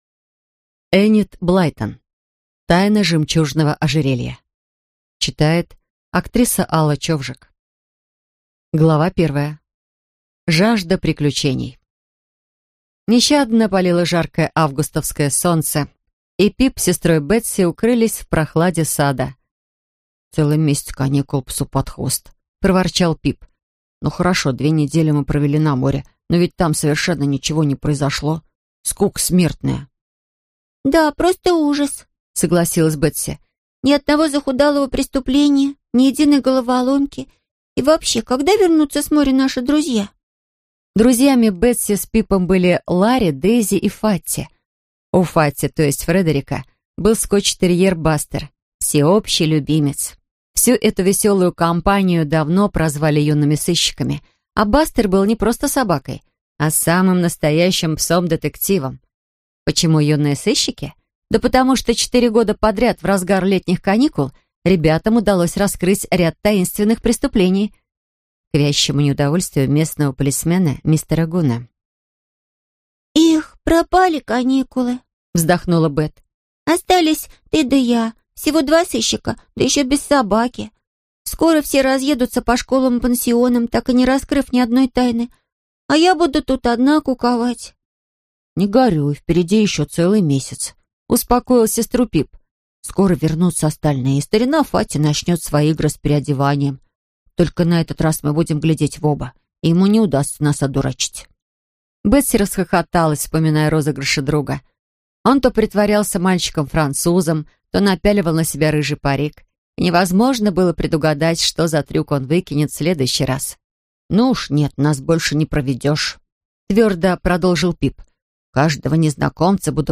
Аудиокнига Тайна жемчужного ожерелья | Библиотека аудиокниг